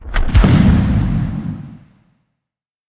sfx_grenade_explode.mp3